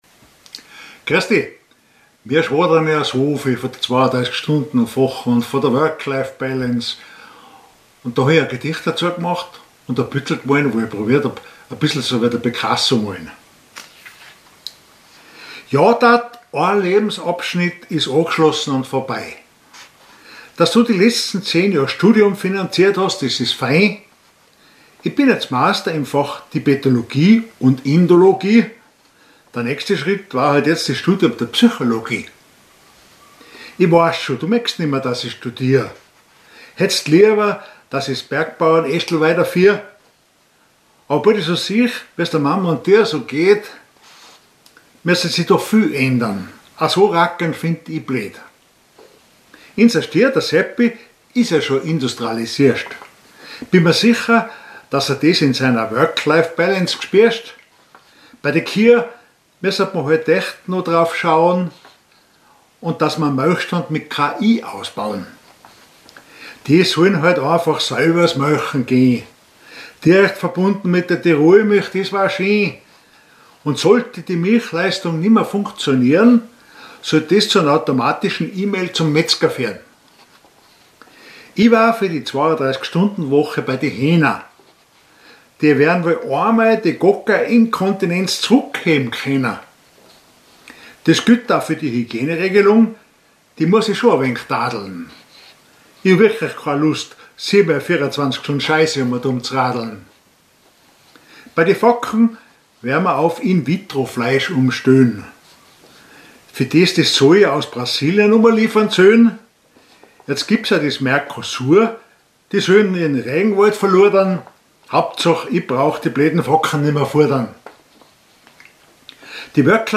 Mundart